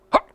jump (1).wav